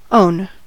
own: Wikimedia Commons US English Pronunciations
En-us-own.WAV